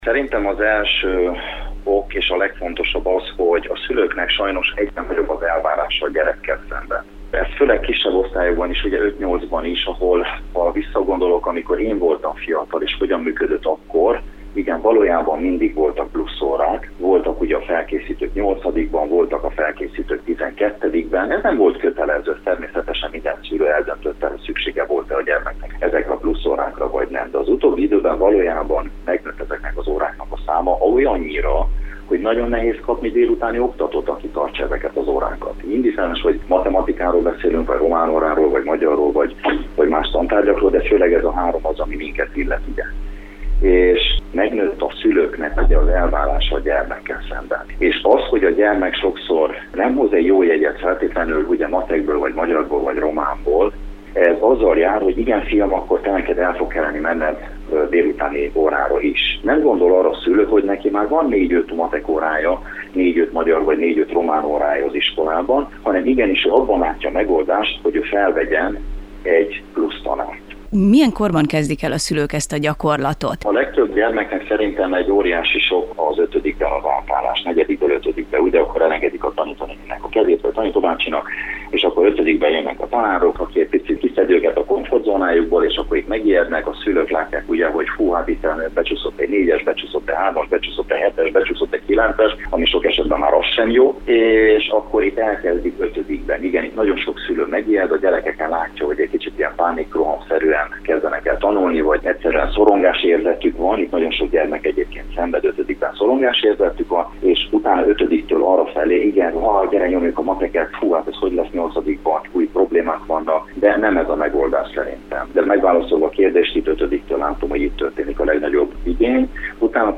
Szülői szövetségi elnököt és tanítót is kérdeztünk.